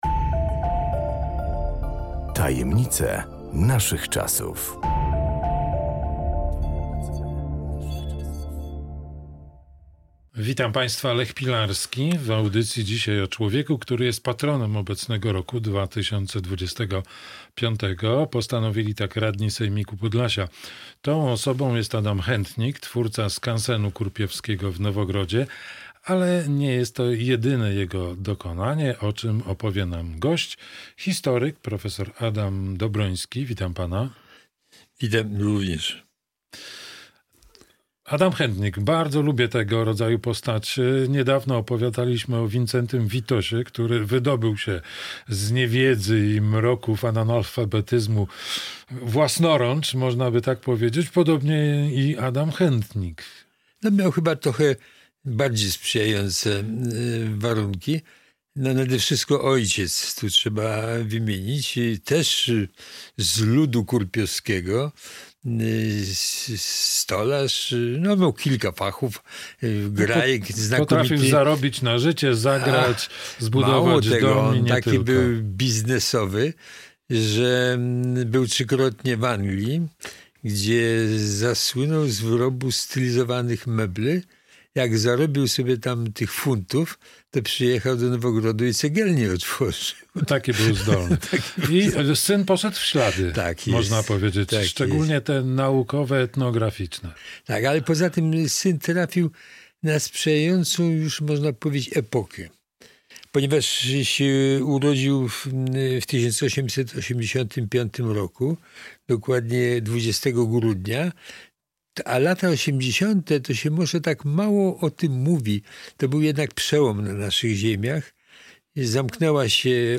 Ale nie jest to jedyne jego dokonanie o czym opowie nam gość historyk